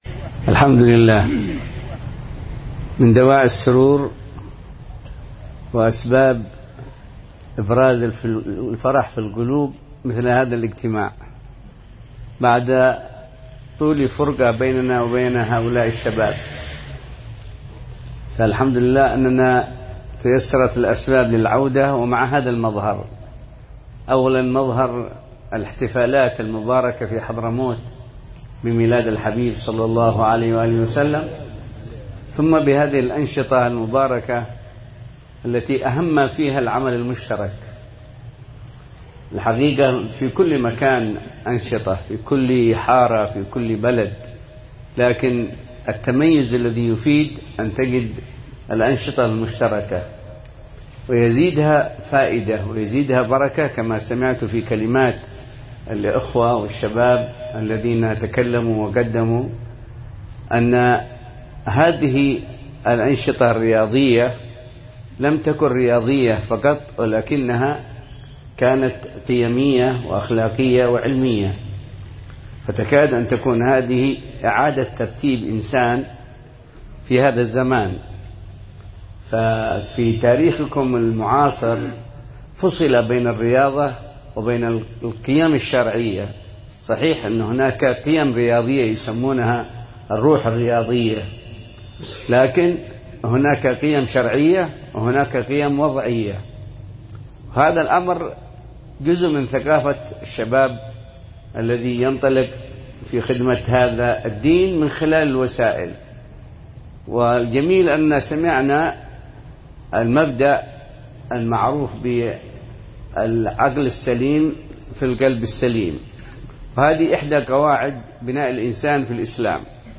كلمة
في اختتام فعاليات الربيع النبوي تنظيم حملة جيل السلامة الواعي بالتعاون مع مدرسة مكارم الأخلاق بالريضة